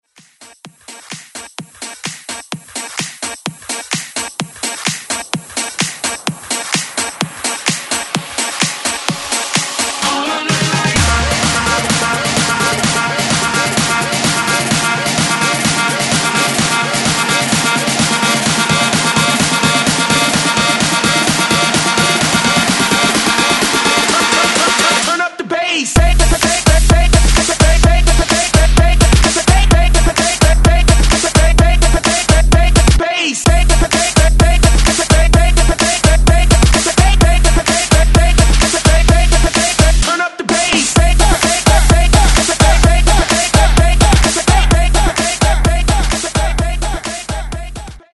84 Bpm Genre: 60's Version: Clean BPM: 84 Time